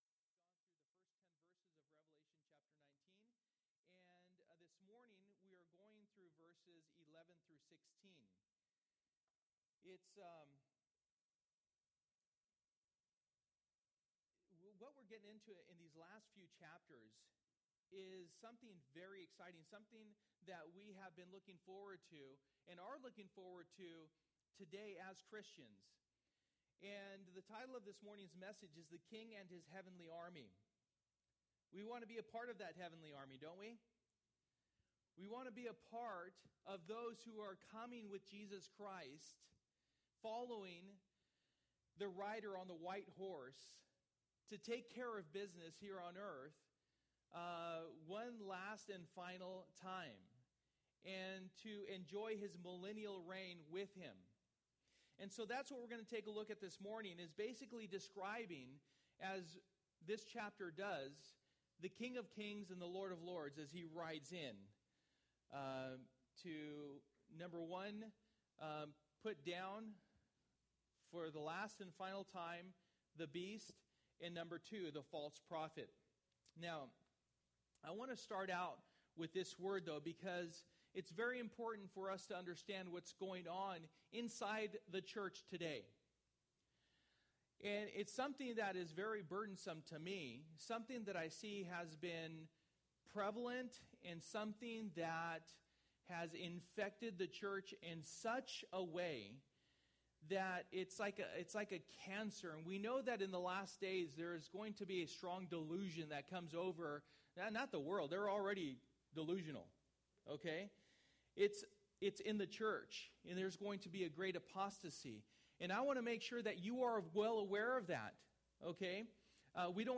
For the Time is Near Passage: Revelation 19:11-16 Service: Sunday Morning %todo_render% « Laying Israel’s Judicial Foundation Pt. 2 Victory!